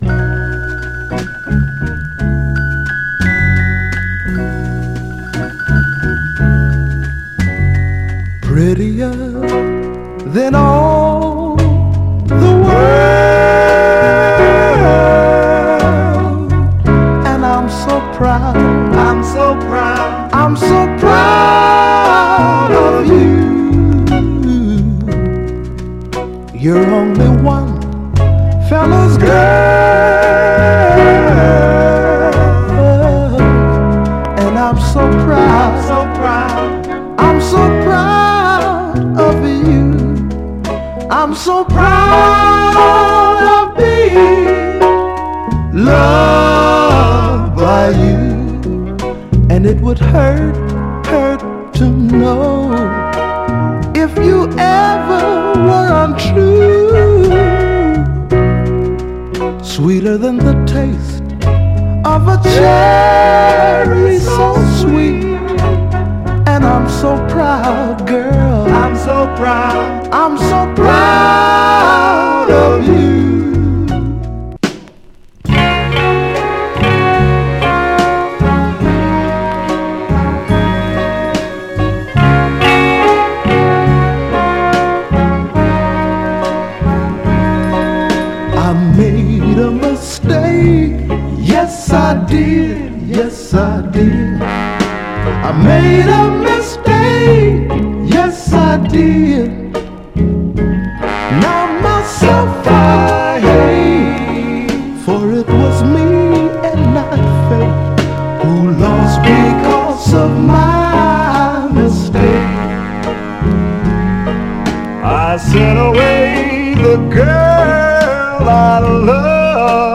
盤は細かいスレ、細かいヘアーラインキズ箇所ありますが、グロスが残っておりプレイ良好です。
※試聴音源は実際にお送りする商品から録音したものです※